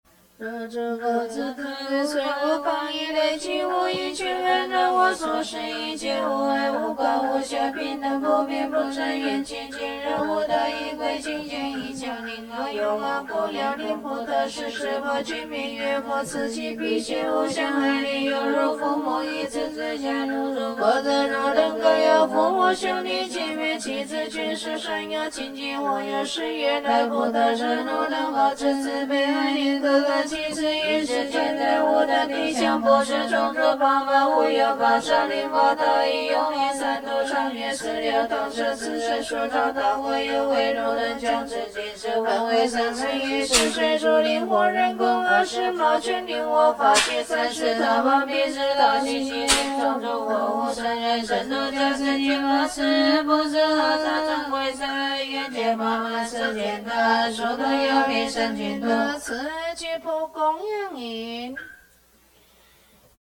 佛教音樂  Mp3音樂免費下載 Mp3 Free Download